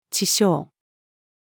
池沼-female.mp3